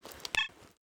pda_holster.ogg